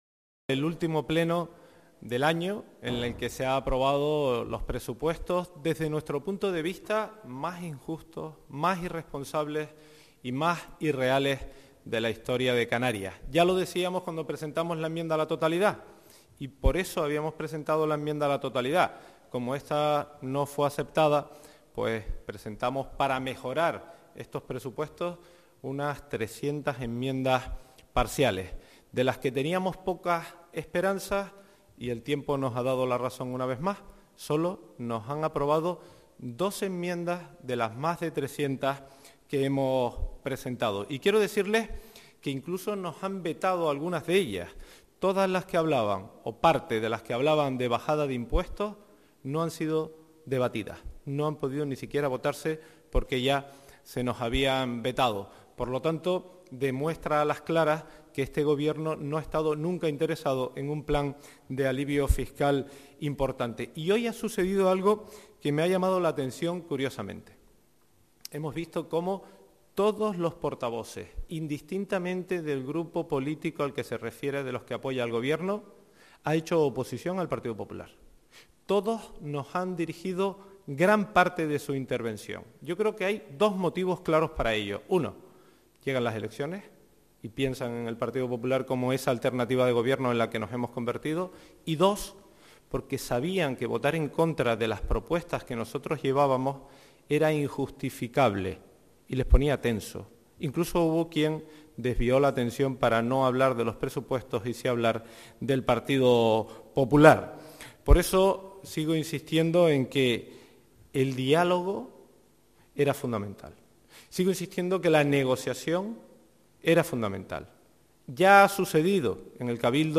Rueda de prensa del GP Popular sobre valoración de los Presupuestos Generales de la Comunidad Autónoma de Canarias 2023 - 14:00